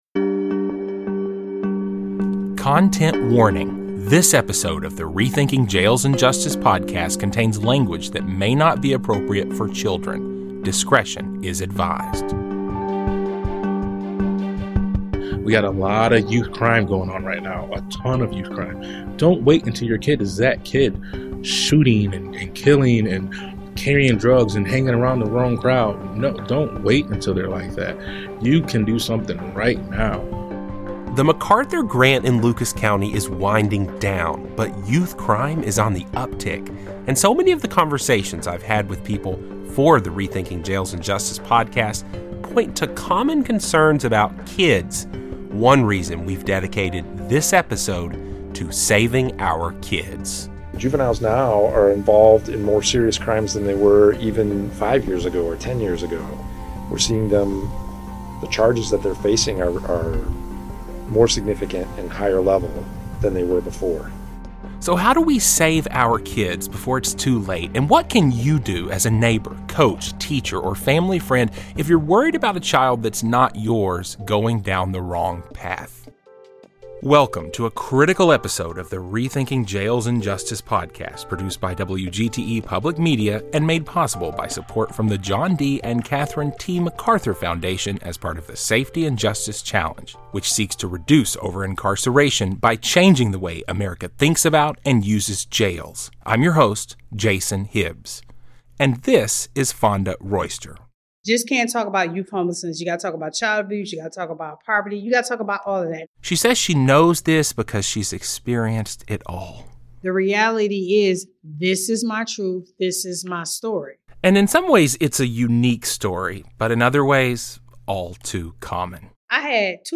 While traditional temptations continue to lure some youth into “the streets,” newer challenges pose new risks for our kids. Community leaders and activists share how their counter-programming initiatives bring children and their parents into groups focused on healthy community activities. Representatives from Toledo Public Schools discuss how their schools address immediate needs of students and parents beyond the classroom.